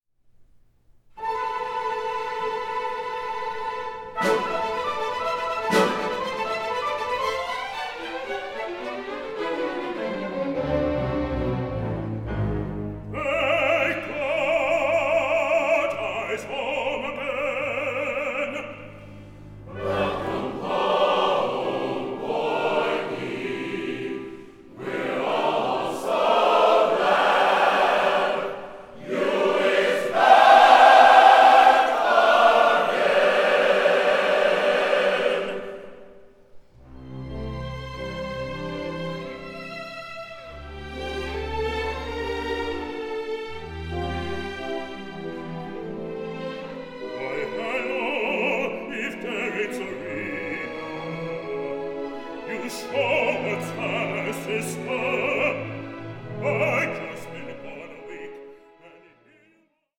THE ULTIMATE AMERICAN “FOLK OPERA”
a live recording